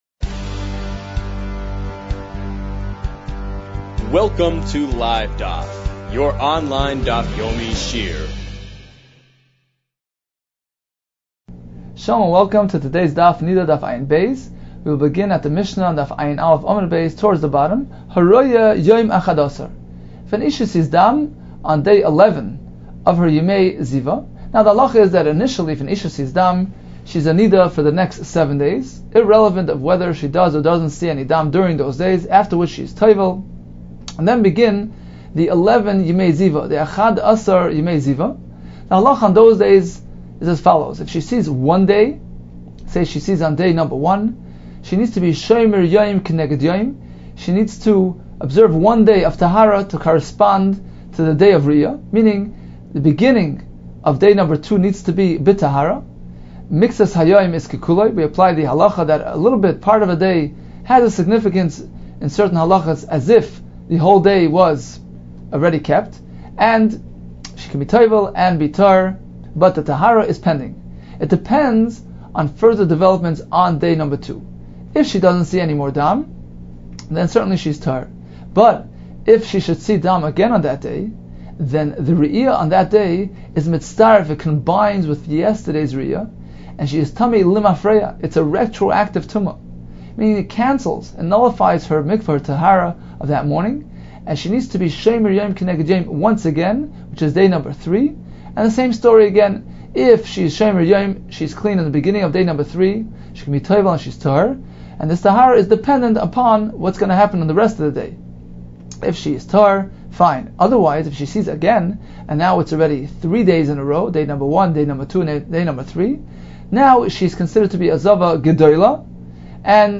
Niddah 72 - נדה עב | Daf Yomi Online Shiur | Livedaf